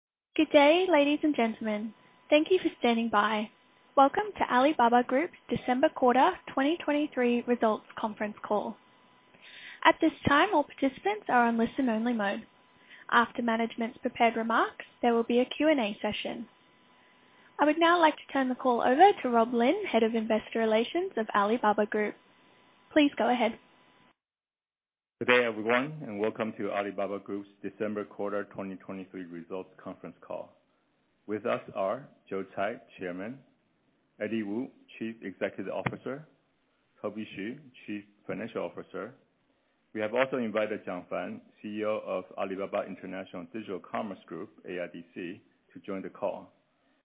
例如将阿里巴巴2023年12月份季度业绩电话会议进行中文同传：